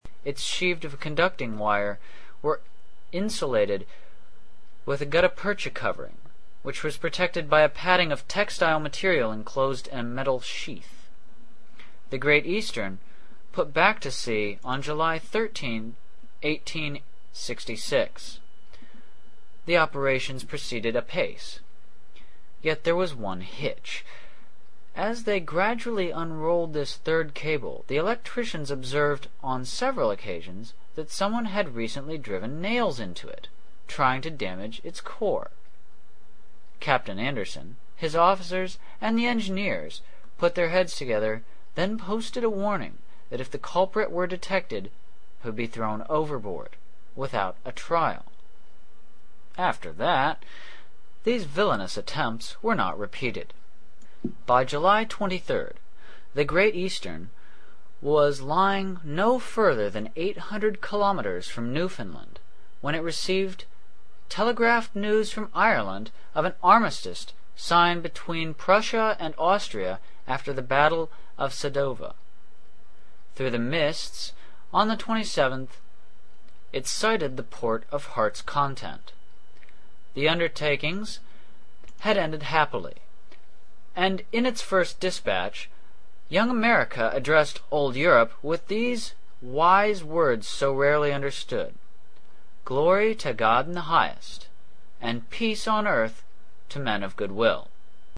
英语听书《海底两万里》第533期 第33章 北纬47.24度, 西经17.28度(8) 听力文件下载—在线英语听力室